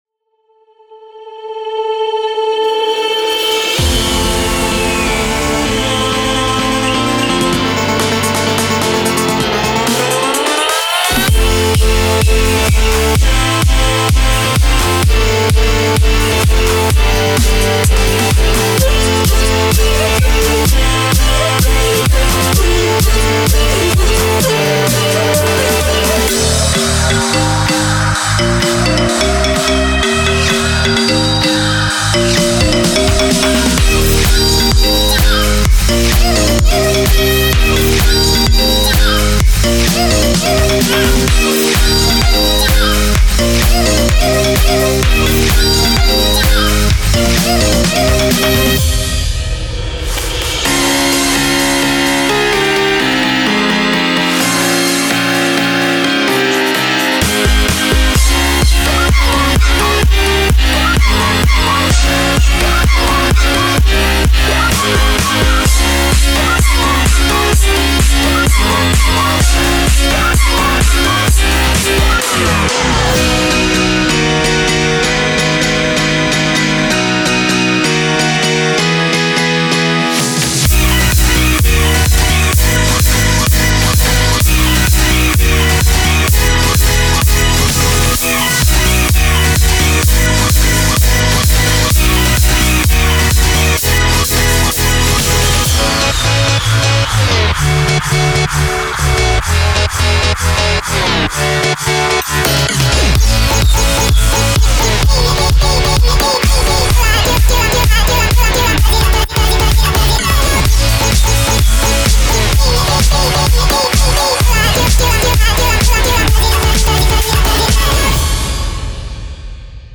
•5个完整的制作套件，带有鼓，合成器，低音，琴键，人声和FX音圈
•10个声乐斩循环